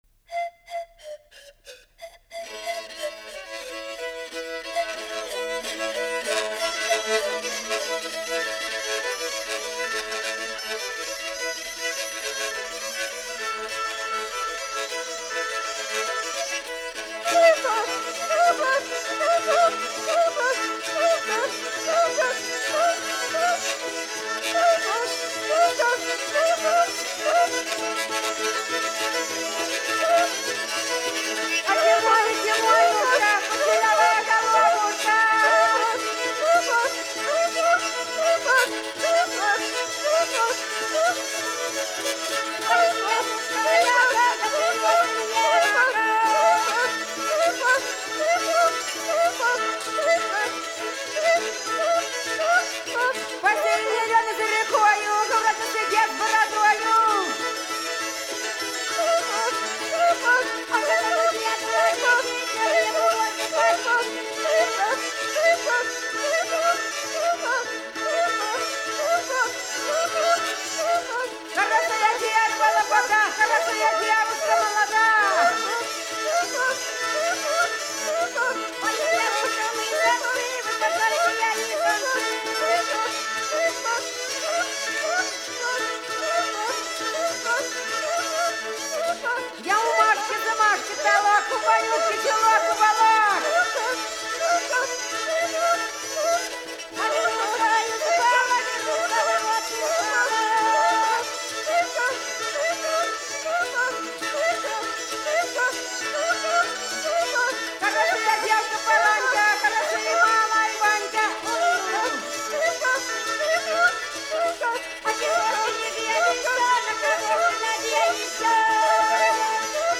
Голоса уходящего века (Курское село Илёк) Тимоня (рожок, инструментальный наигрыш)